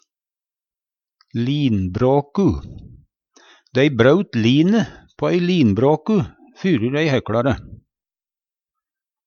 linbråku - Numedalsmål (en-US)